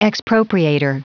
Prononciation du mot expropriator en anglais (fichier audio)
Prononciation du mot : expropriator